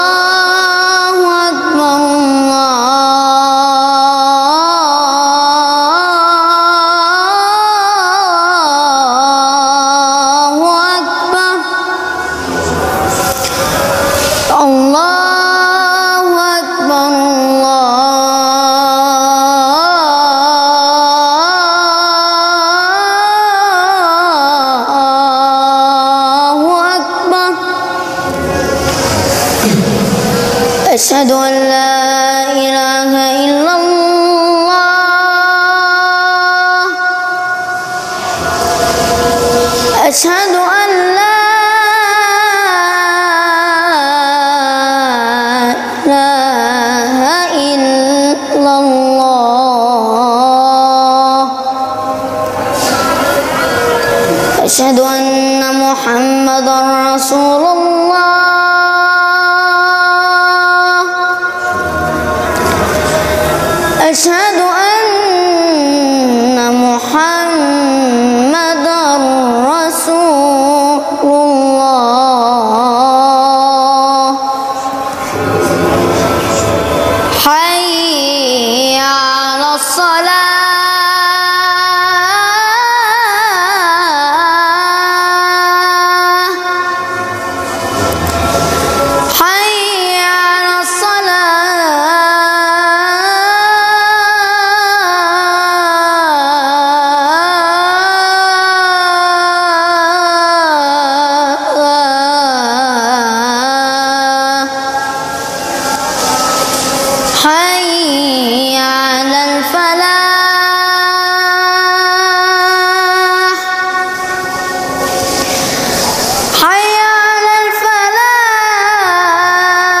ADHĀN - Böneutropet
Man ropar ut adhān med hög röst så att människor kan höra det.
Adhan.mp3